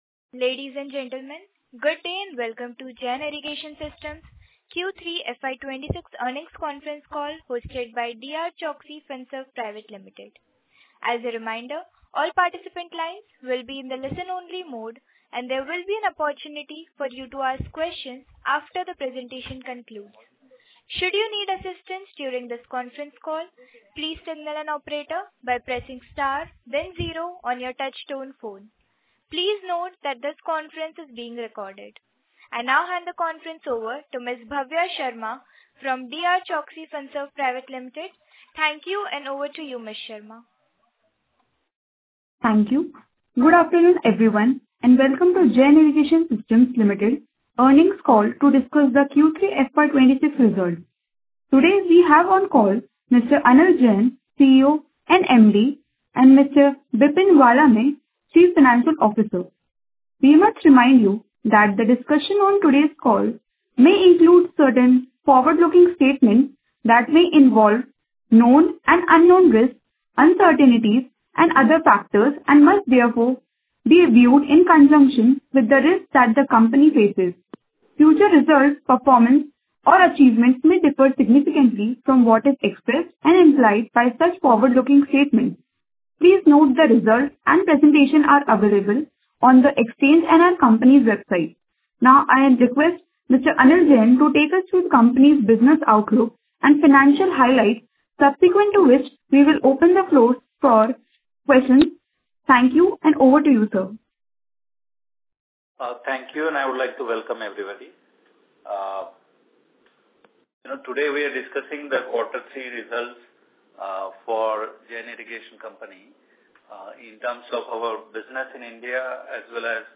Earnings Conference Call
Jain Irrigation Q3FY26 Call Recording.mp3